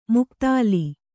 ♪ muktāli